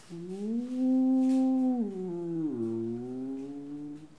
Les sons ont été découpés en morceaux exploitables. 2017-04-10 17:58:57 +02:00 720 KiB Raw History
bruit-animal_13.wav